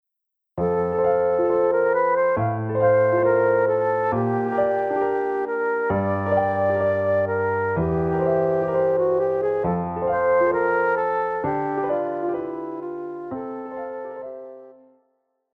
Classical
Flute
Piano
Solo with accompaniment